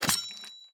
cortar_dedo.wav